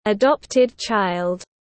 Con nuôi tiếng anh gọi là adopted child, phiên âm tiếng anh đọc là /əˈdɒp.tɪd tʃaɪld/.
Adopted child /əˈdɒp.tɪd tʃaɪld/